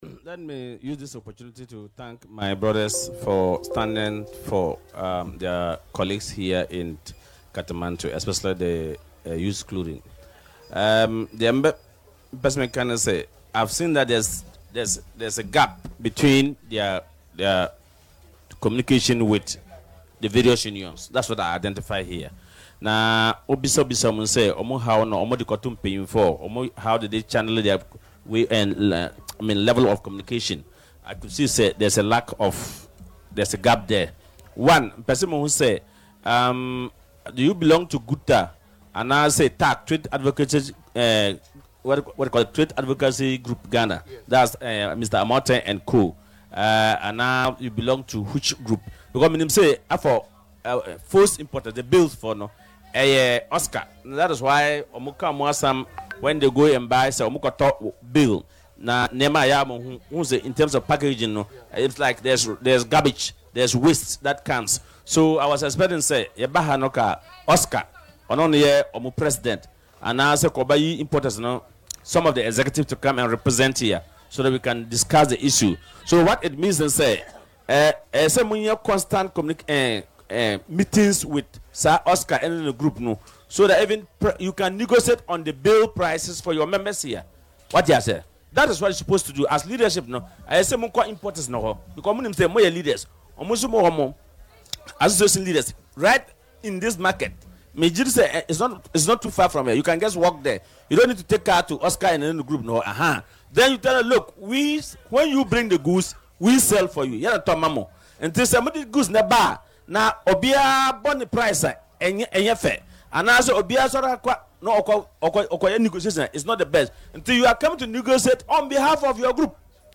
Speaking on Adom FM’s Dwaso Nsem